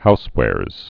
(houswârz)